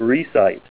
This alphabetical listing of R minerals include synonyms of accepted mineral names, pronunciation of that name, name origins, and locality information.
Say Ruizite  View Ruizite Images Gallery  CaMn+++Si2O6(OH)·2(H2O) Approved IMA 1977
RUIZITE.WAV